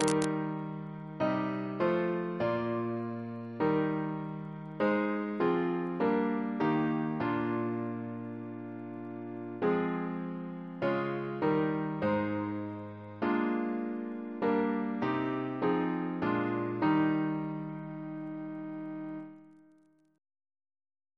Double chant in E♭ Composer: Sir Charles V. Stanford (1852-1924), Professor of Music, Cambridge Reference psalters: ACB: 384; ACP: 146; CWP: 20; H1982: S39; RSCM: 129